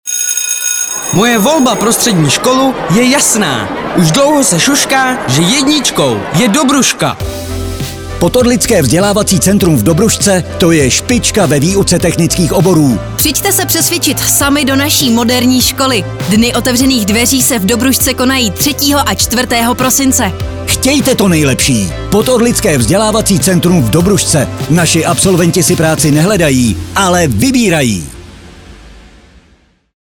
Audio pozvánka z rádia
sspvc-dod-2021-reklama.mp3